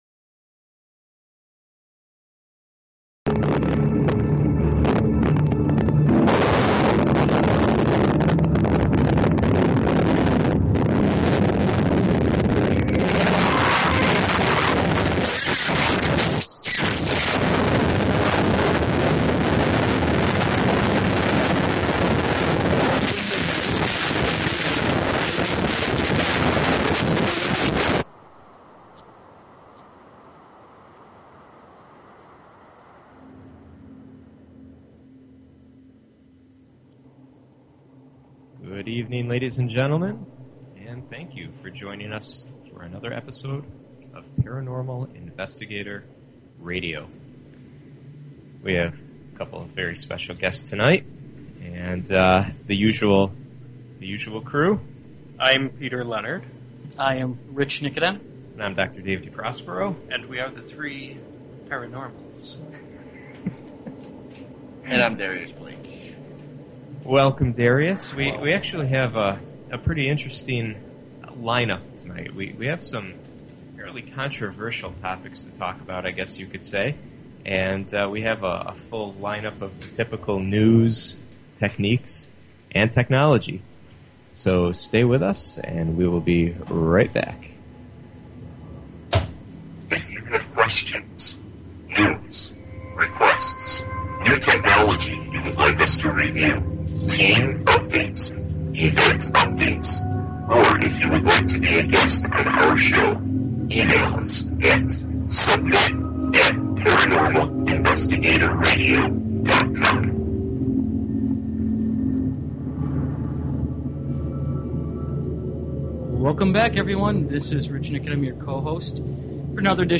Paranormal Investigator Radio